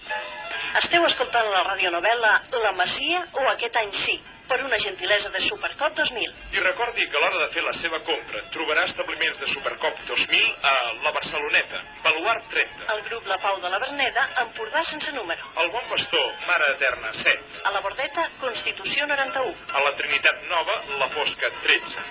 Careta de la radionovel·la «La masia o aquest any, sí!»,, amb publicitat